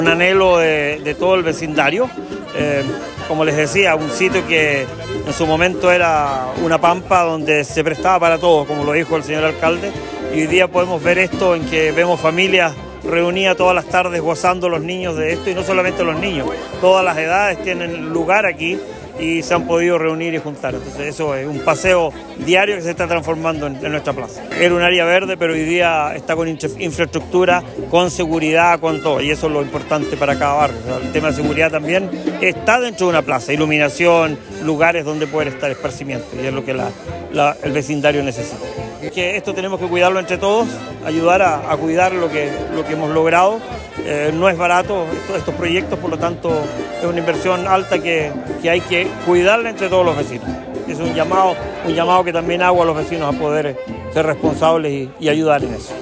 Este viernes, con la presencia de autoridades nacionales, regionales, locales y vecinos del sector, se llevó a cabo la inauguración de la nueva Plaza Los Aromos en Osorno, ubicada en el sector oriente de la ciudad.